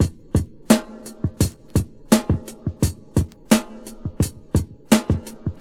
• 86 Bpm Fresh Breakbeat F Key.wav
Free breakbeat - kick tuned to the F note. Loudest frequency: 1210Hz
86-bpm-fresh-breakbeat-f-key-tNf.wav